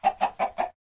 Sound / Minecraft / mob / chicken2